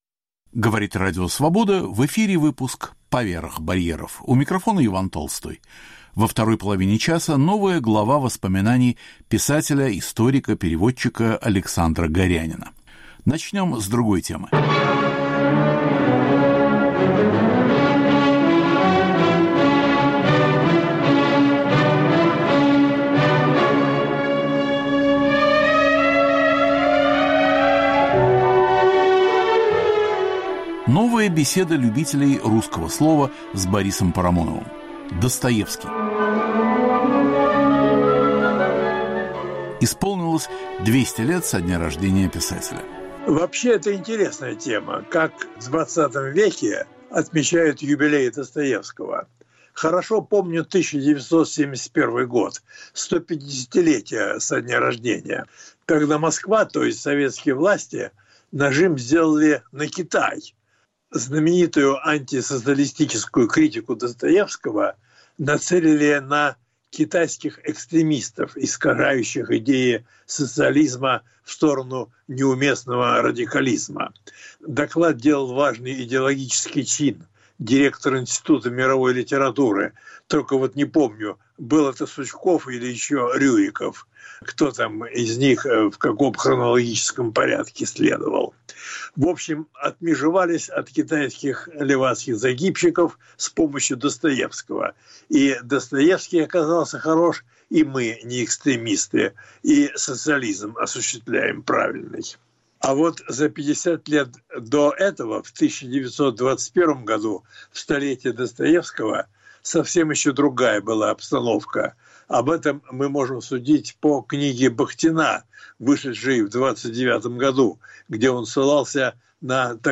Разговор о Достоевском. К 200-летию со дня рождения писателя.